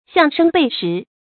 向声背实 xiàng shēng bèi shí
向声背实发音
成语注音 ㄒㄧㄤˋ ㄕㄥ ㄅㄟˋ ㄕㄧˊ